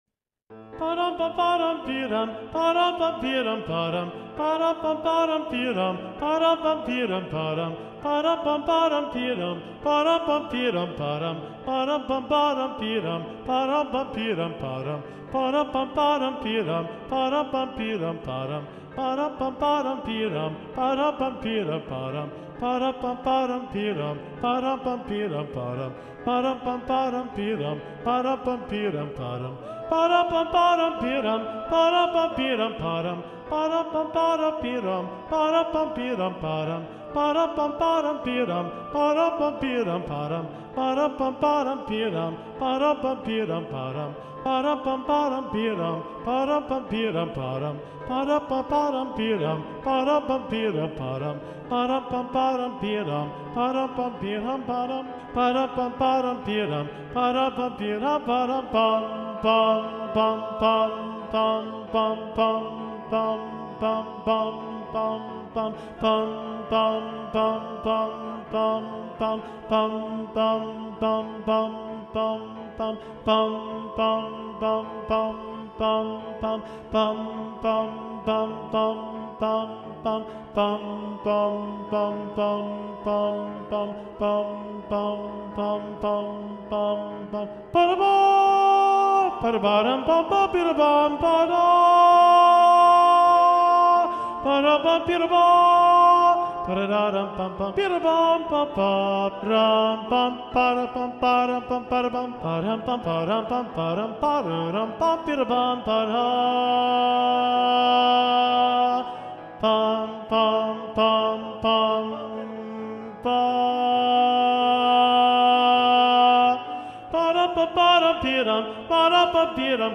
- Œuvre pour chœur à 7 voix mixtes (SSAATTB) + piano
SATB Tenor 2 (chanté)